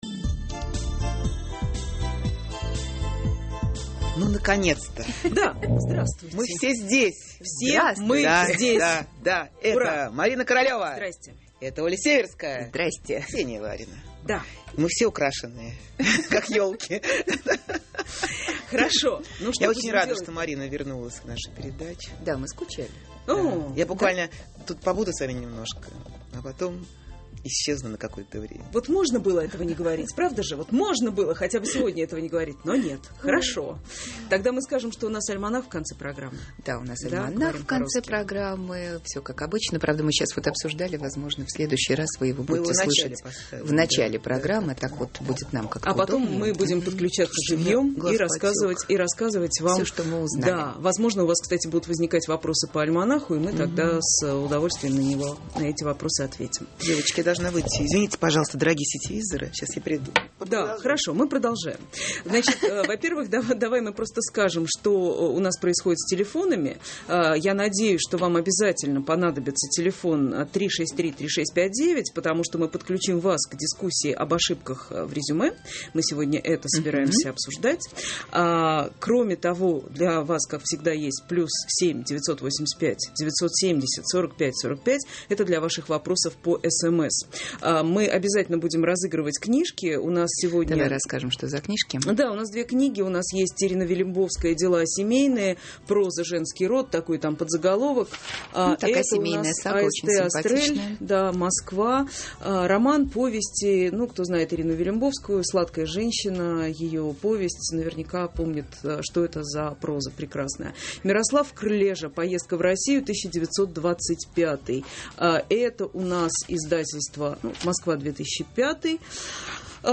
Говорим по-русски. Передача-игра